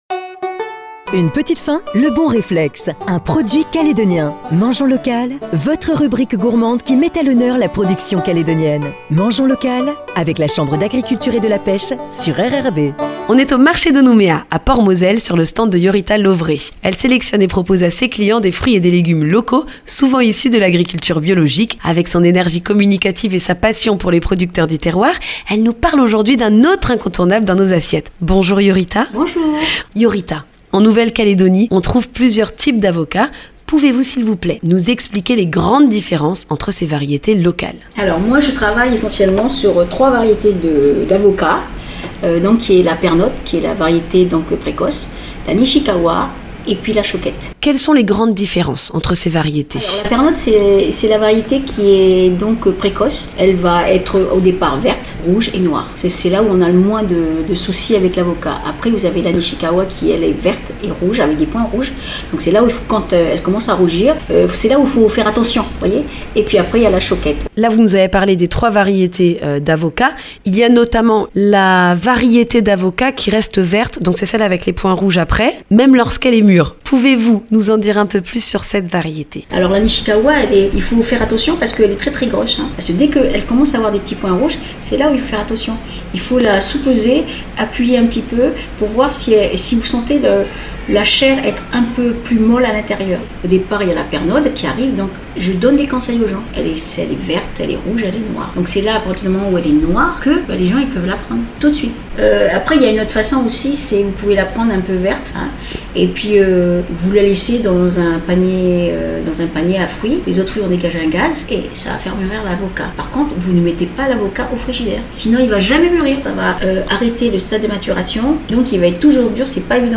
Pour ce nouvel épisode de Mangeons local, direction le marché de Nouméa, à Port-Moselle